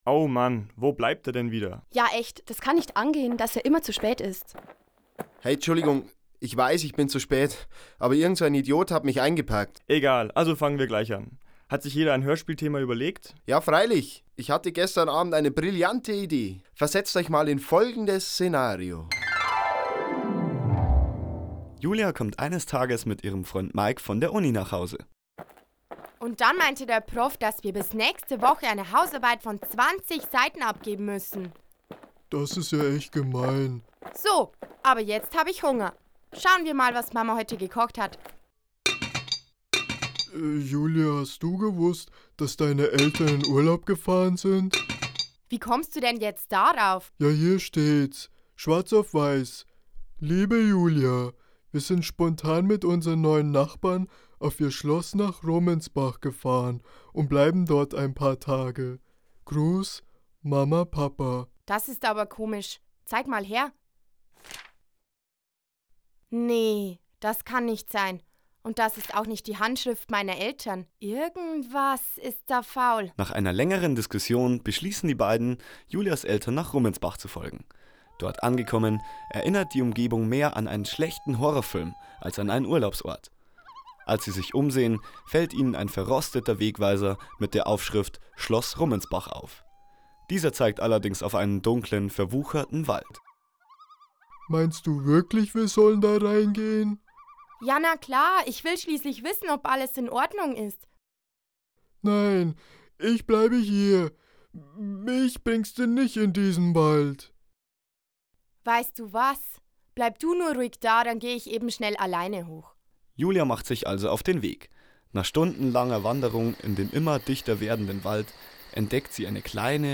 hoerspiel_eineUnglaubwuerdigeGeschichte_komplett.mp3